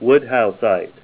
Help on Name Origin: Name Origin: Named for Charles D. Woodhouse (1888-1975), U.S. mineral collector Help on Name Pronunciation: Name Pronunciation: Woodhouseite + Pronunciation
Say WOODHOUSEITE Help on Synonym: Synonym: ICSD 100137   Kehoeite - quartz, gypsum and sphalerite   PDF 37-469